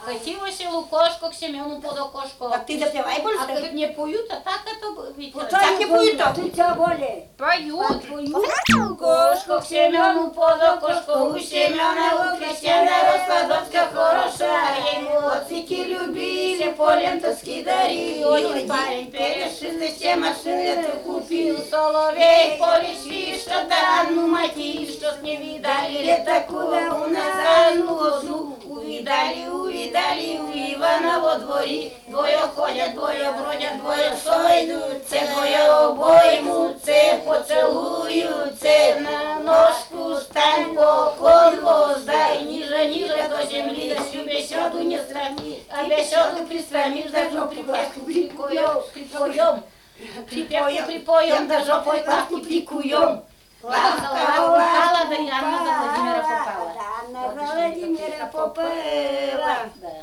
01 «Катилося лукошко» – припевка на вечереньках в исполнении фольклорного ансамбля д. Кеба Лешуконского р-на Архангельской обл.